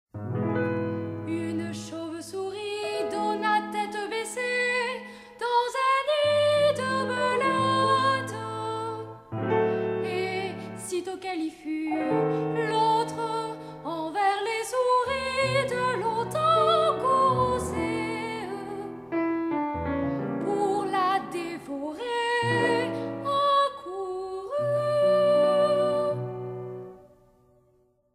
Cantate de la chauve-  souris